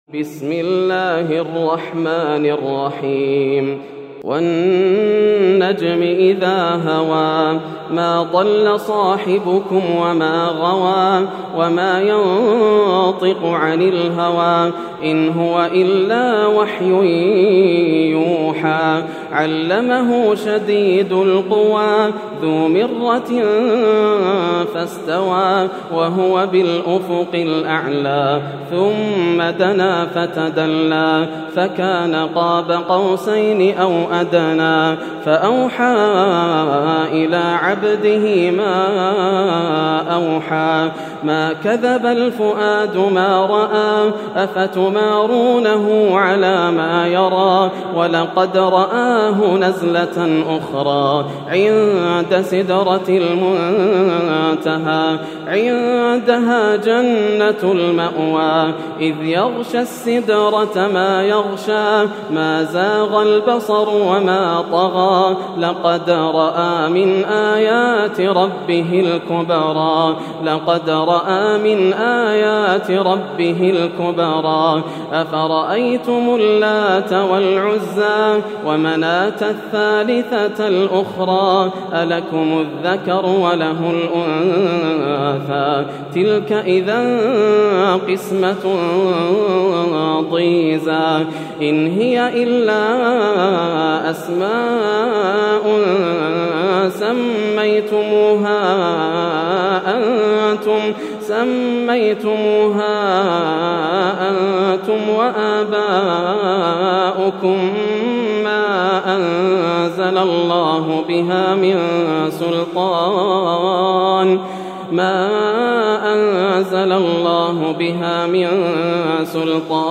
سورة النجم > السور المكتملة > رمضان 1431هـ > التراويح - تلاوات ياسر الدوسري